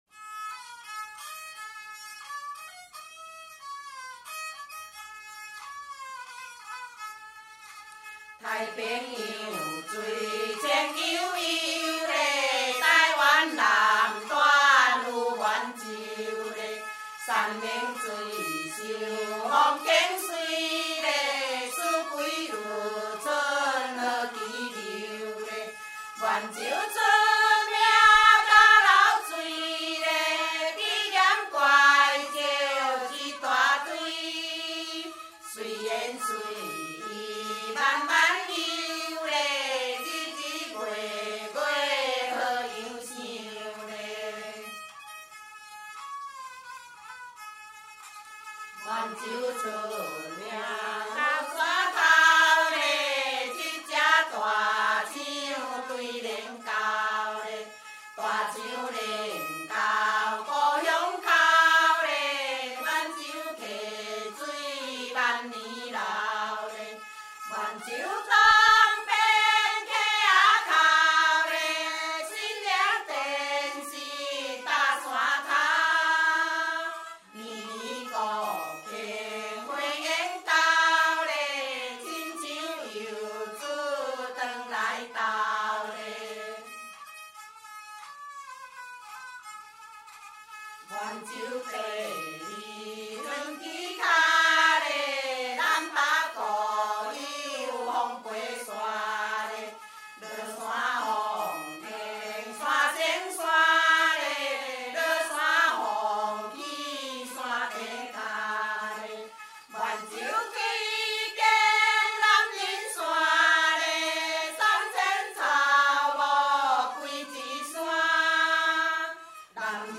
◎制作群 ： 演出：月琴,壳仔弦｜
18首原汁原味的素人歌声，在粗哑中满怀真性情，那是上一代的回忆，这一代的情感，下一代的宝藏！
弹唱